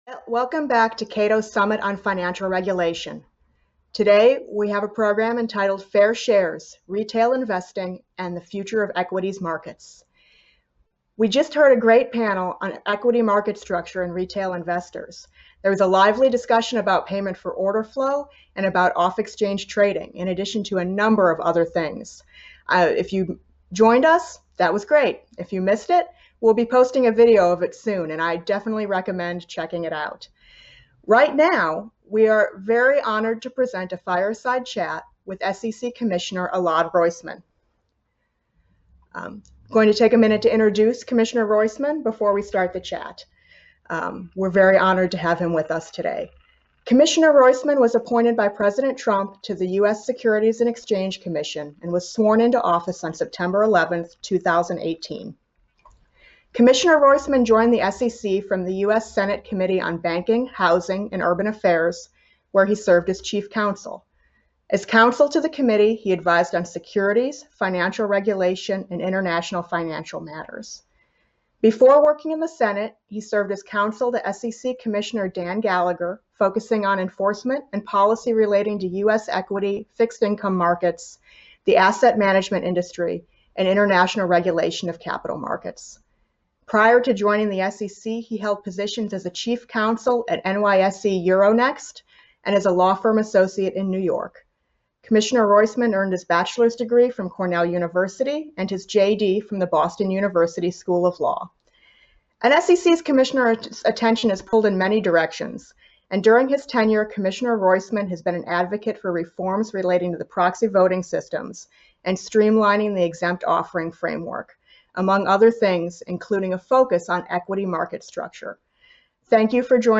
Fair Shares: Retail Investors and the Future of Equities Markets: Fireside Chat with Commissioner Elad Roisman of the U.S. Securities and Exchange Commission
Join us for an outstanding virtual program featuring leading policymakers and experts at Cato’s seventh annual Summit on Financial Regulation.